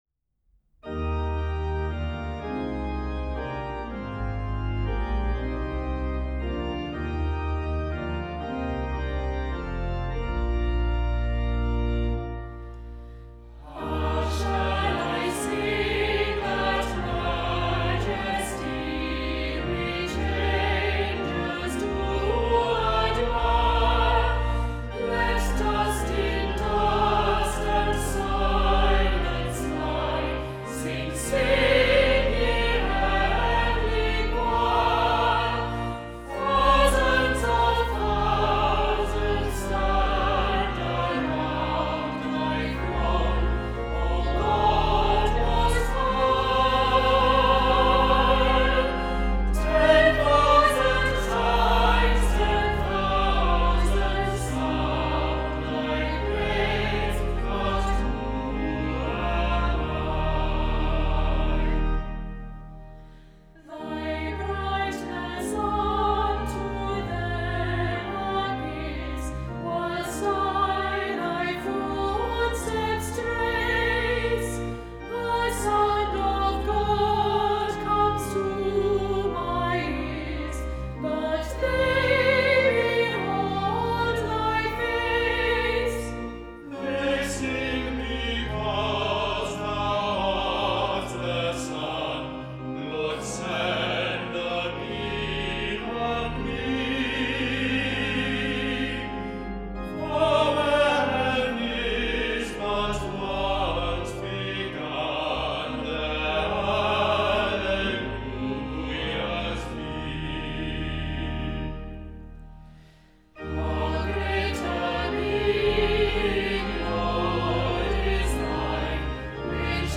Offertory hymn, How shall I sing that Majesty